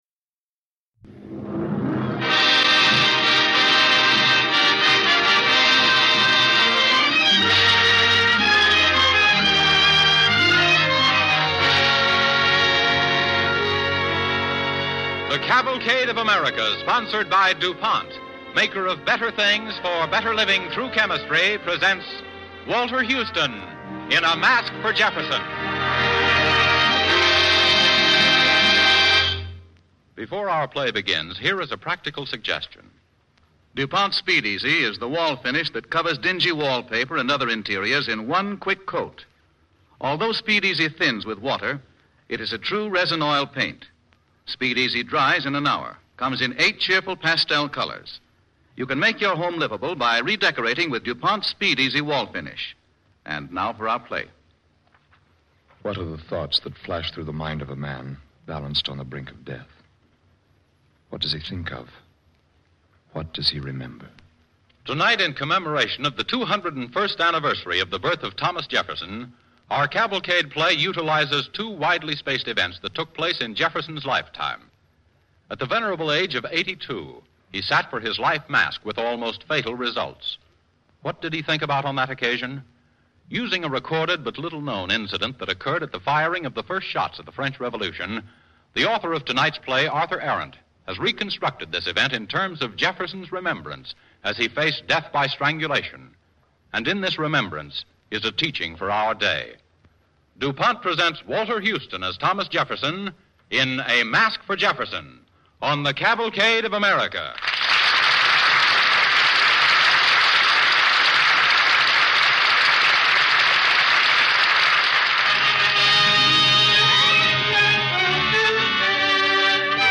starring Walter Houston and Hans Conried